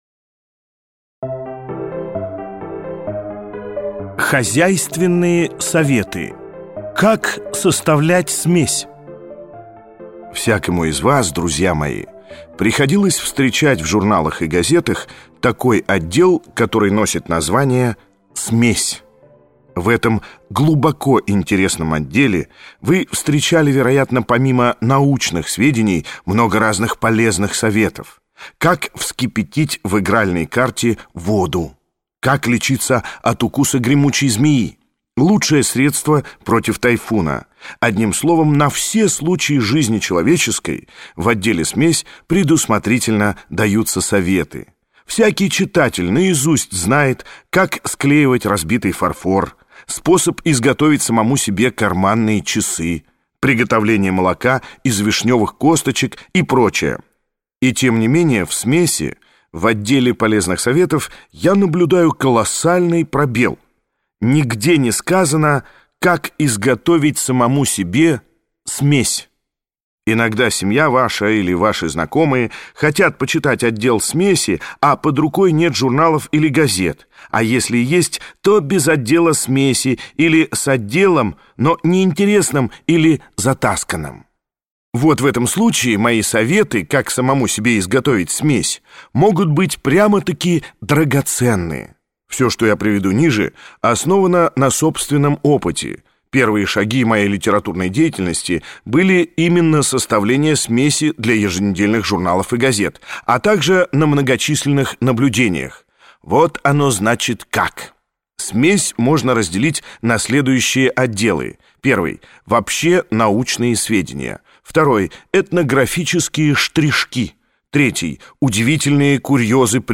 Аудиокнига О хороших, в сущности, людях | Библиотека аудиокниг